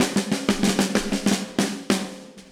AM_MiliSnareC_95-02.wav